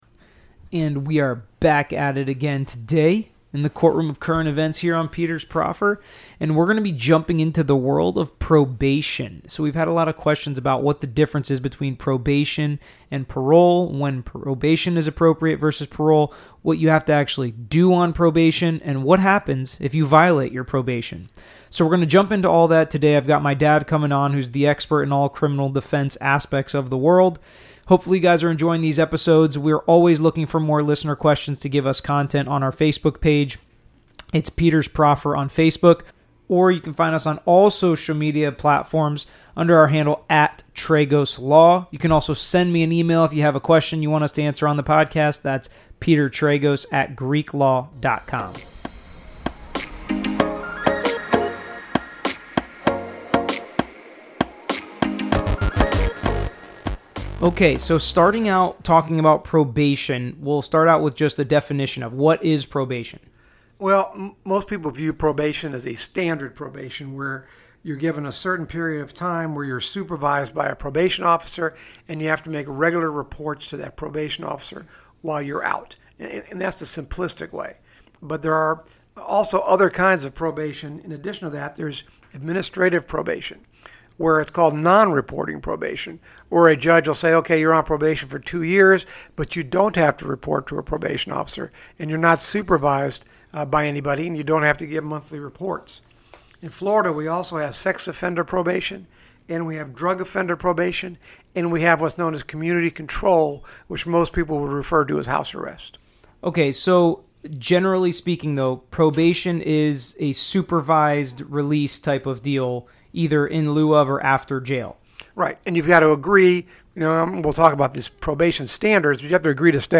In this week’s episode, Florida criminal lawyers at Tragos Law answer frequently asked questions about probation, including: What is probation?